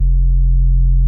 Bass (No Mistakes).wav